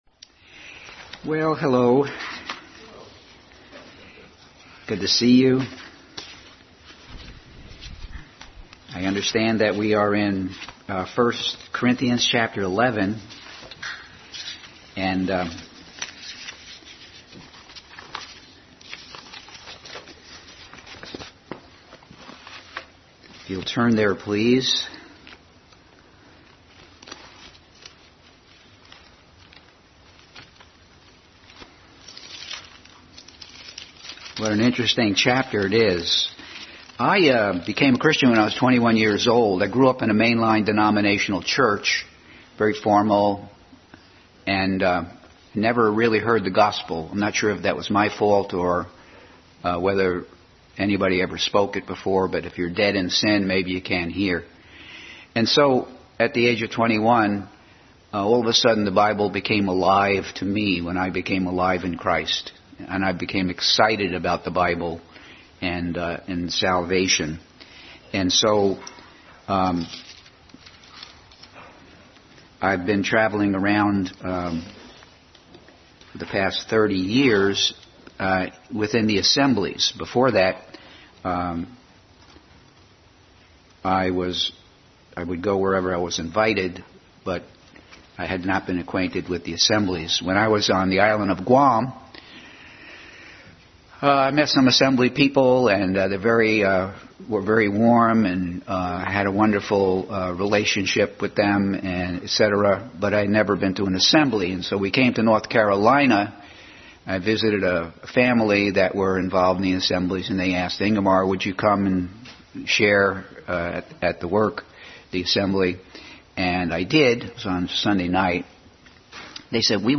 Adult Sunday School Class continued study in 1 Corinthians.
1 Corinthians 11:13-26 Service Type: Sunday School Adult Sunday School Class continued study in 1 Corinthians.